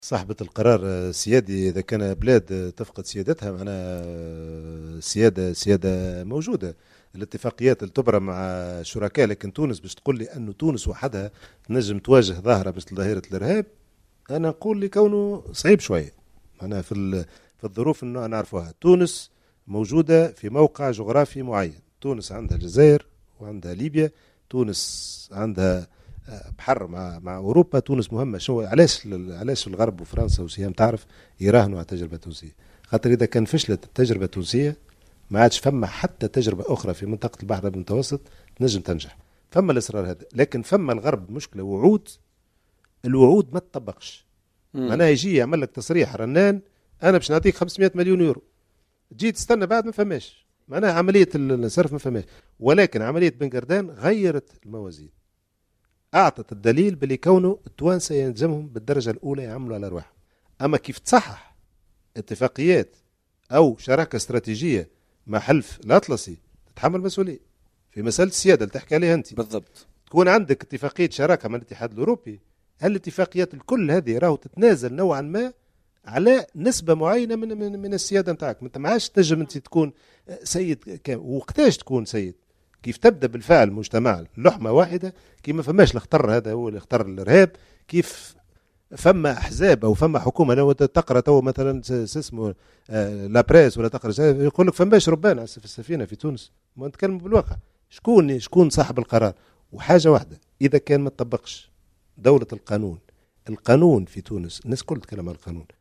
تصريح للجوهرة أف أم في برنامج بوليتكا